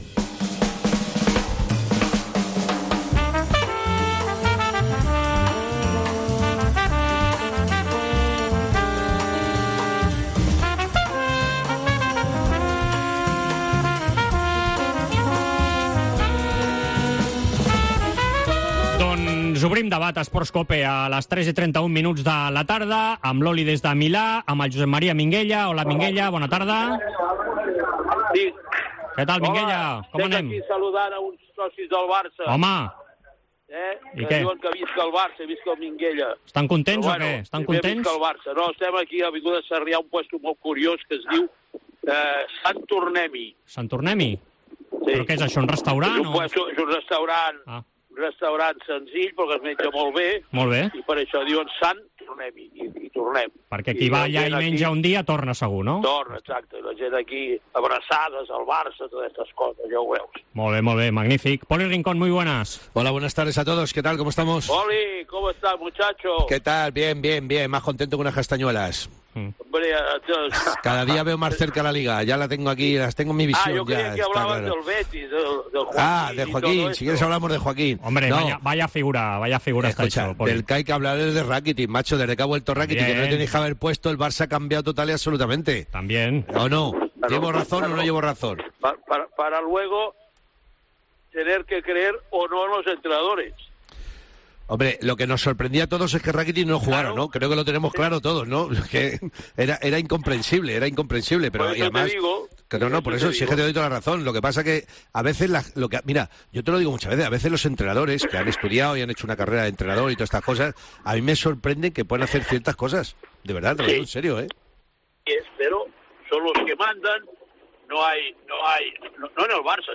El Debate Esports Cope de los lunes